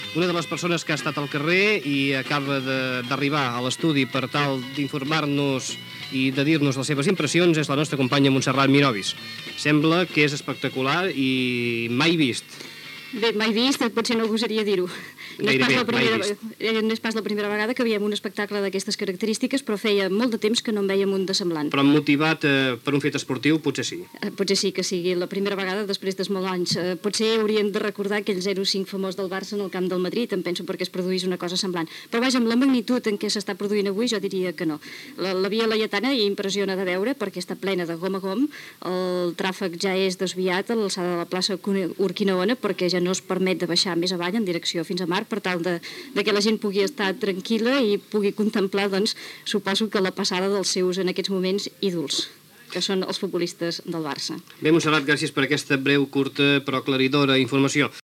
Transmissió de l'arribada del F:C: Barcelona amb la recopa d'Europa de futbol masculí.
Ambient als carrers de Barcelona (Via Laietana)
Informatiu